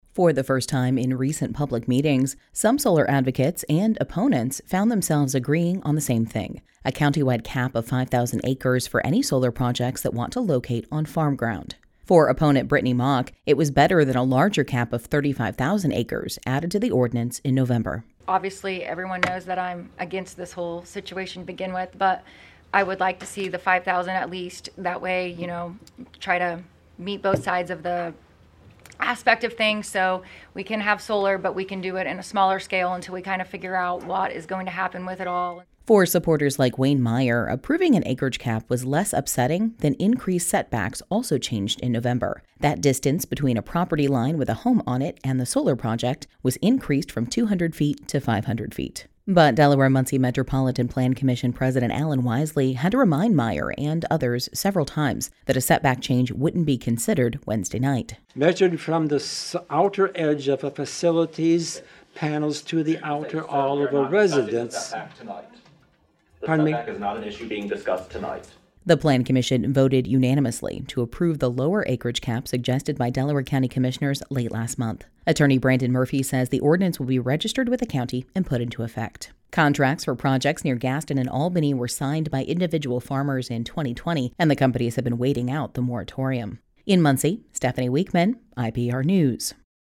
For the first time in recent public meetings, some solar advocates and opponents found themselves agreeing on the same thing – a countywide cap of 5,000 acres for any solar projects that want to locate on farm ground.